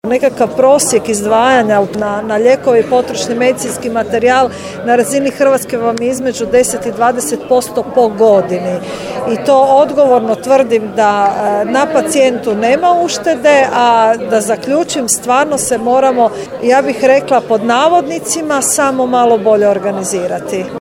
Razgovor je snimljen, recimo to tako, na marginama prošlotjednog sastanka o Razvojnom sporazumu za Sjever Hrvatske održanom u Daruvaru.